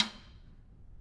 Percussion
Snare2-taps_v1_rr1_Sum.wav